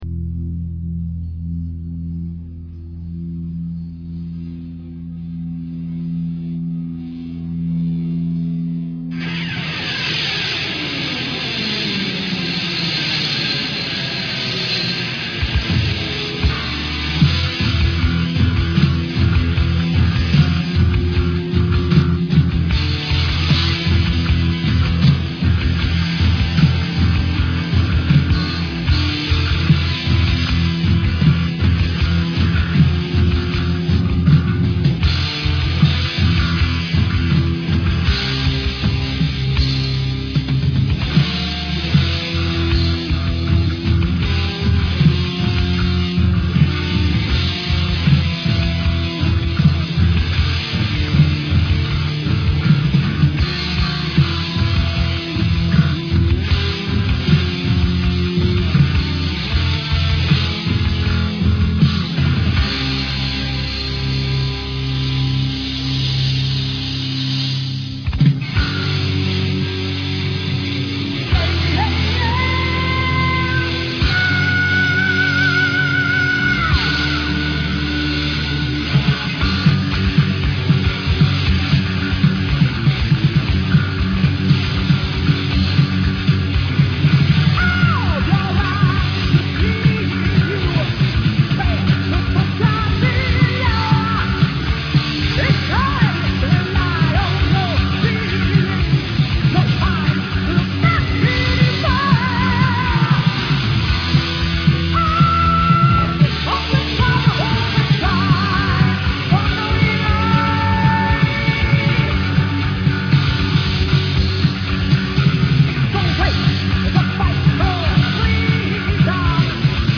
Unveröffentlichter Demo-Song.